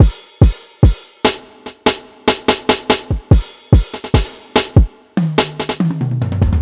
life stuff drumfill.wav